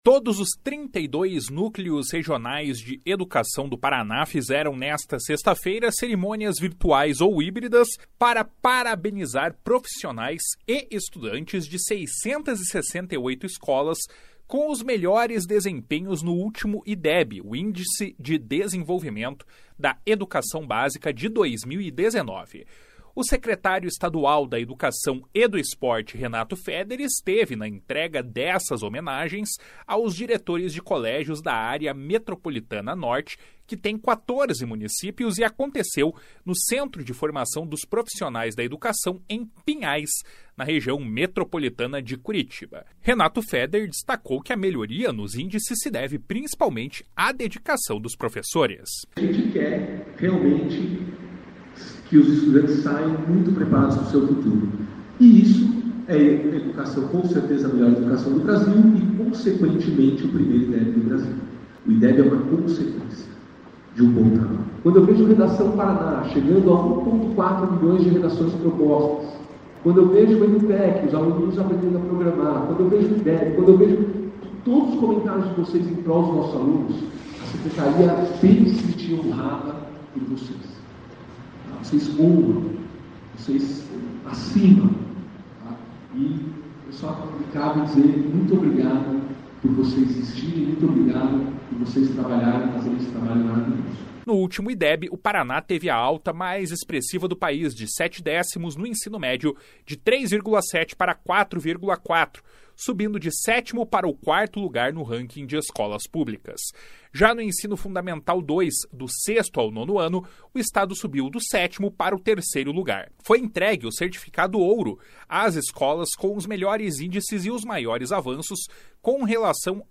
Renato Feder destacou que a melhoria nos índices se deve principalmente a dedicação dos professores. // SONORA RENATO FEDER // No último Ideb, o Paraná teve a alta mais expressiva do país, de sete décimos, no ensino médio, de 3,7 para 4,4, subindo de sétimo para o quarto lugar no ranking de escolas públicas.